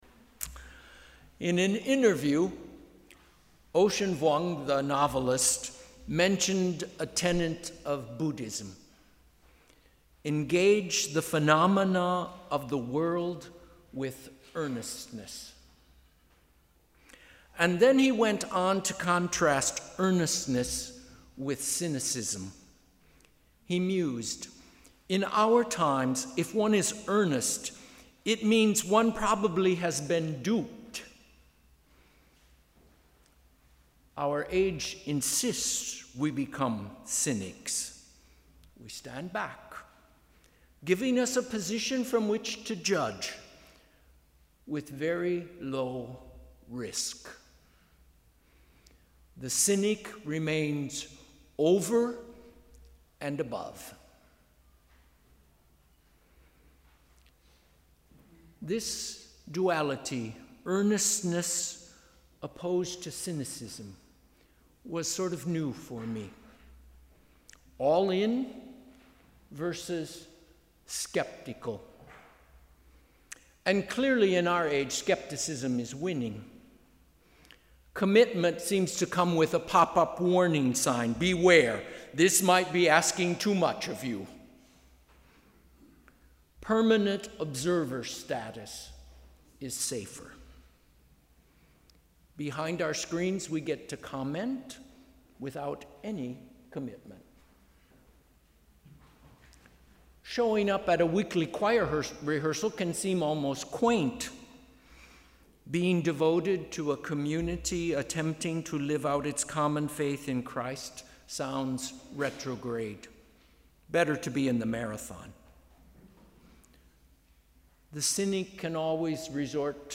Sermon: ‘Raise us up’